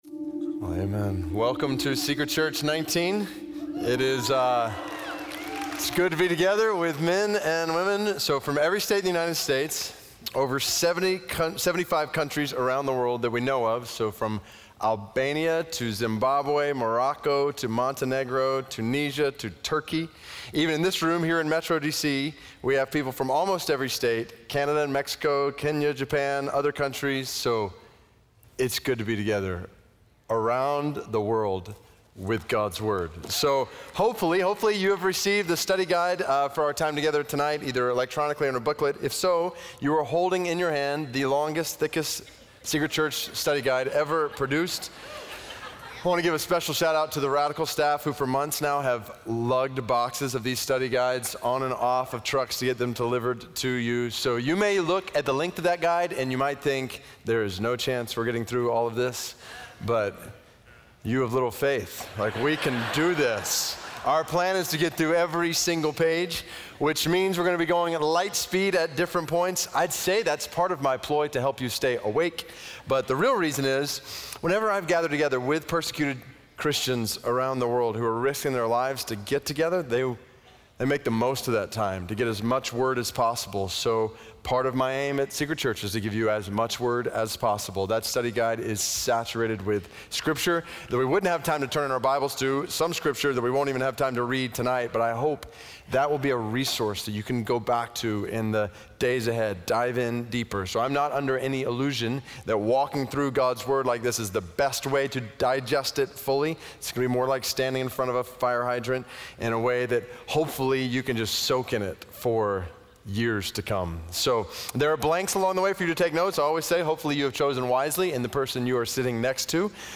In this session of Secret Church 19, Pastor David Platt introduces the topic of prayer, fasting, and the pursuit of God.